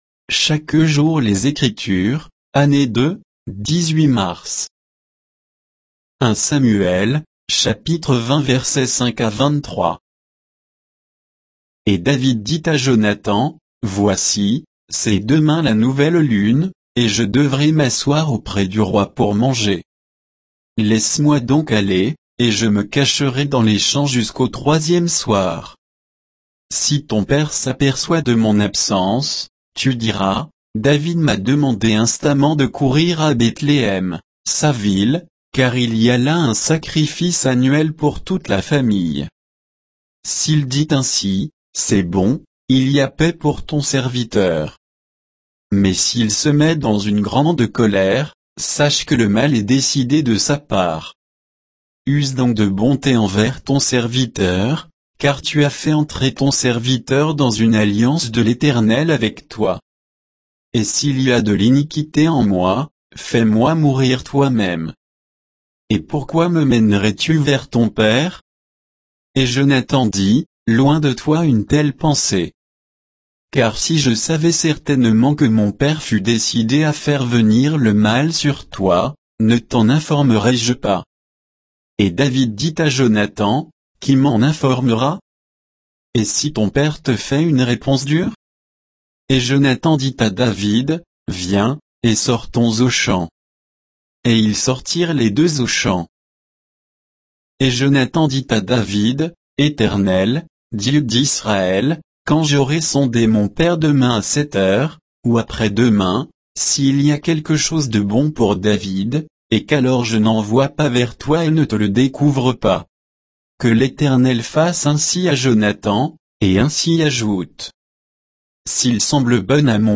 Méditation quoditienne de Chaque jour les Écritures sur 1 Samuel 20, 5 à 23